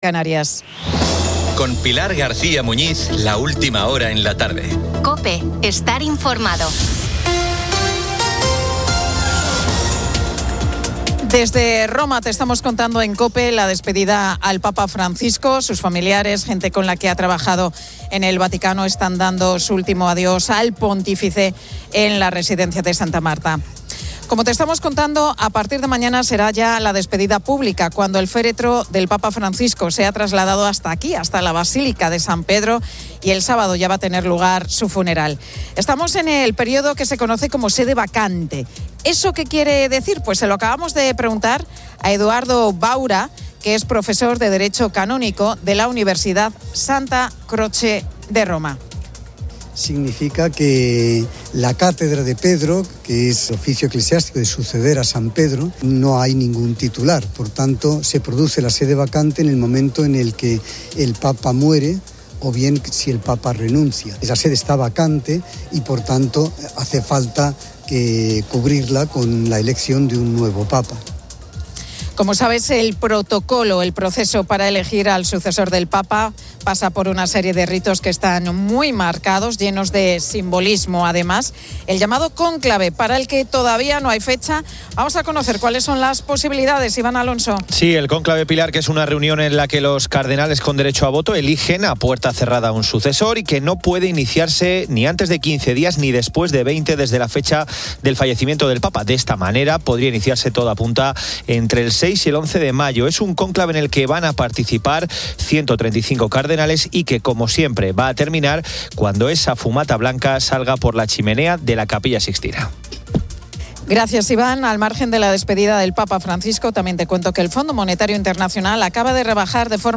La Tarde 17:00H | 22 ABR 2025 | La Tarde Pilar García Muñiz y el equipo de La Tarde de COPE desde Roma por la muerte del Papa Francisco.